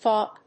/fάp(米国英語), fˈɔp(英国英語)/